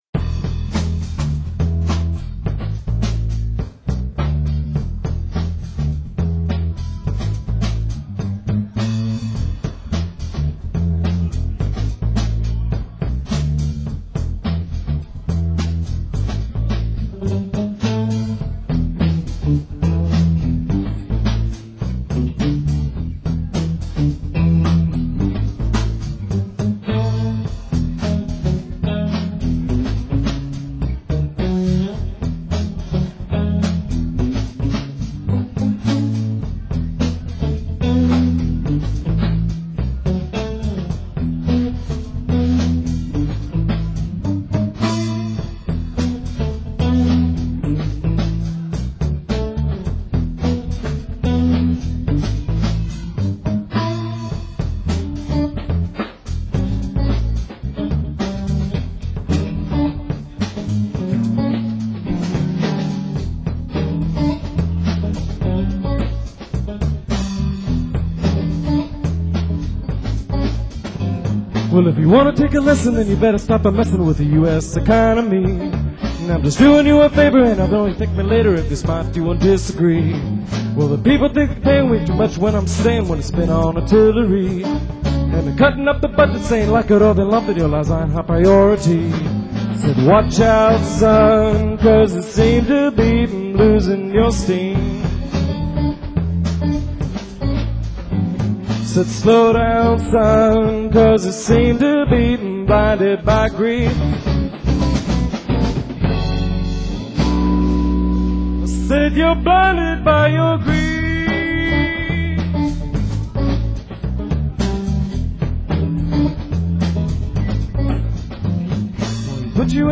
drums
guitar
bass